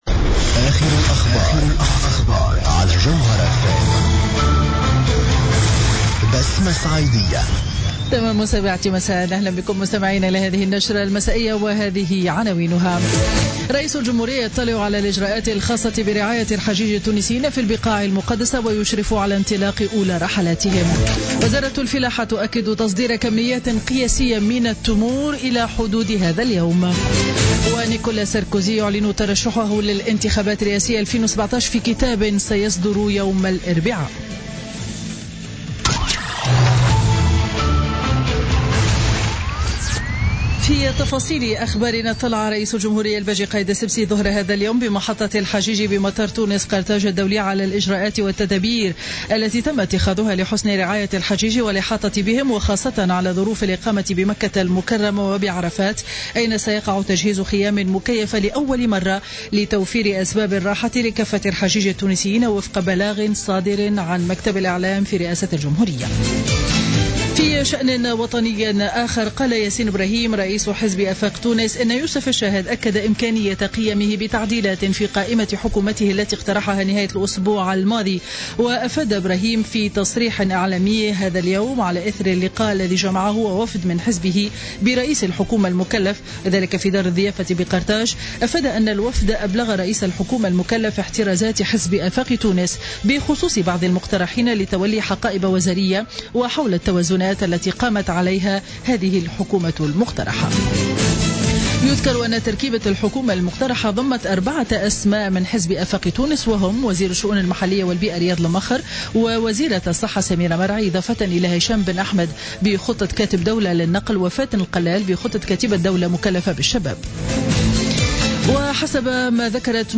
نشرة أخبار السابعة مساء ليوم الاثنين 22 أوت 2016